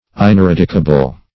Ineradicable \In`e*rad"i*ca*ble\, a.
ineradicable.mp3